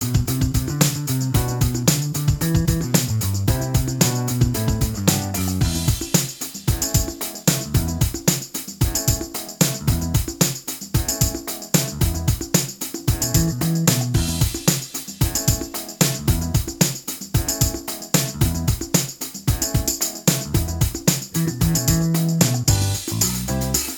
Minus All Guitars Disco 3:12 Buy £1.50